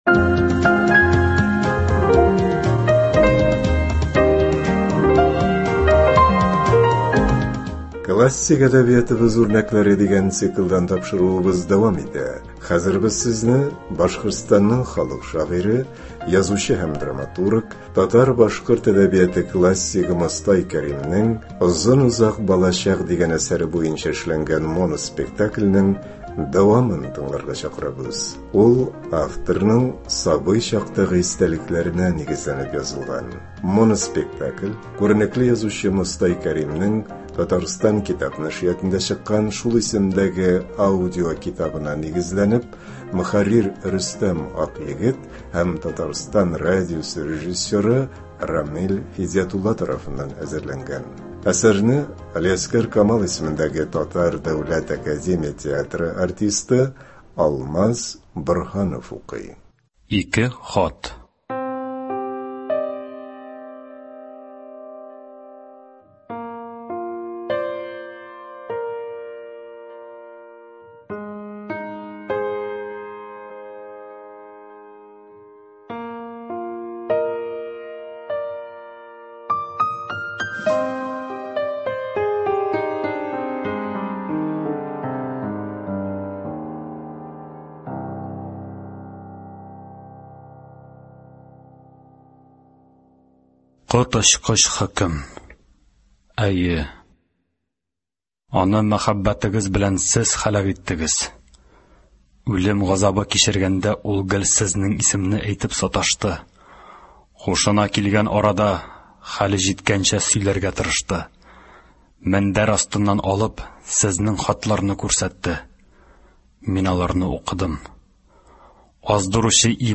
Мостай Кәрим. “Озын-озак балачак”. Моноспектакль.